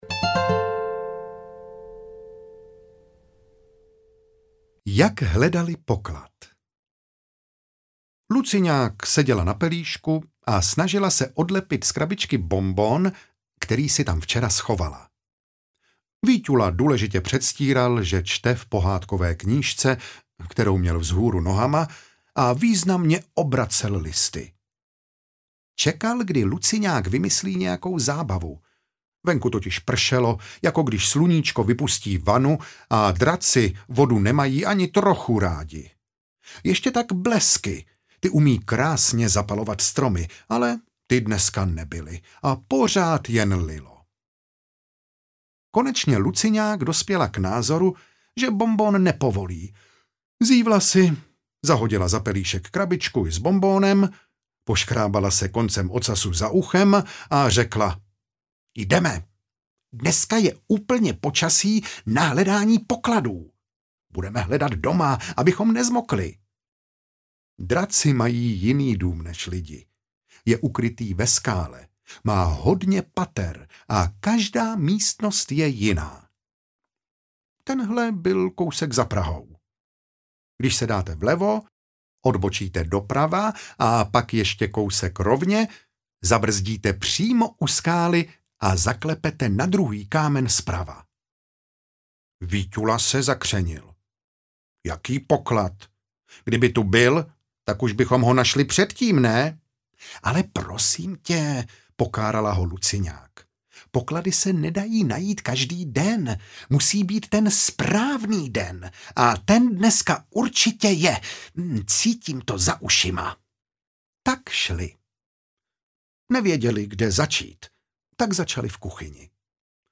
Luciňák a Víťula ... nikdy nezlobí audiokniha
Ukázka z knihy